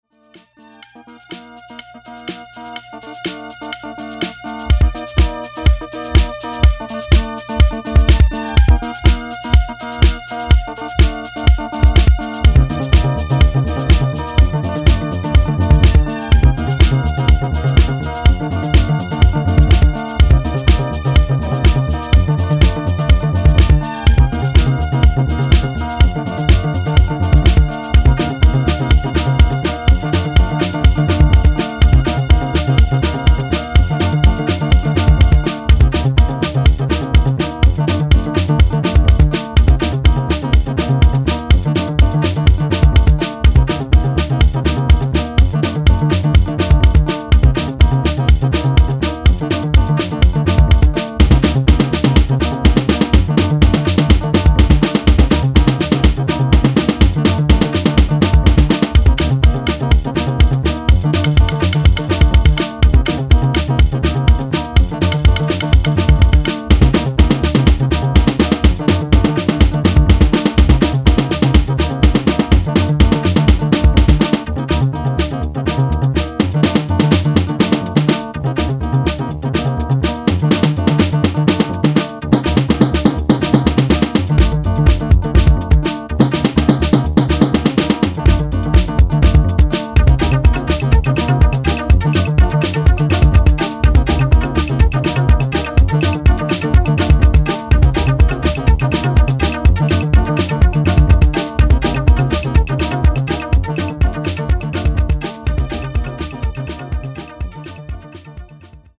催眠的ミニマルリフ、畳み掛けるスネアによる素朴にしてファンキー